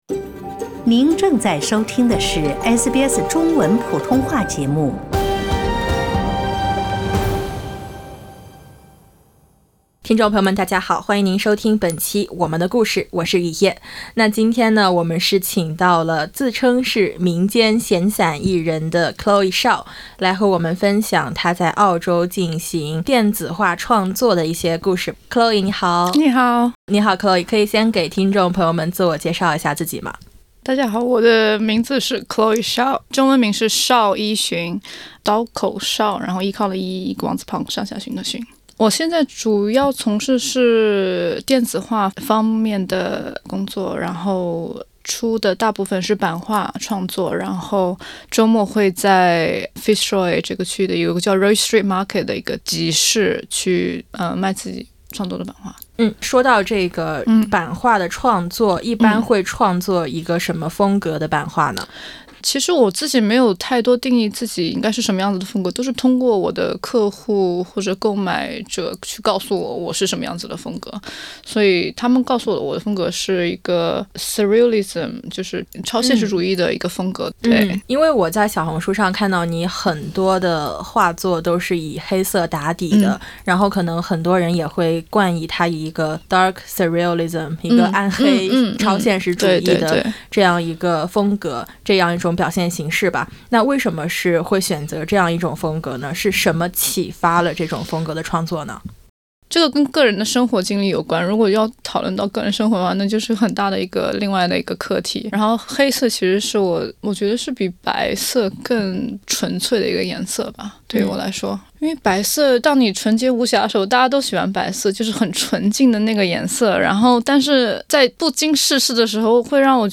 欢迎点击封面音频，收听完整采访。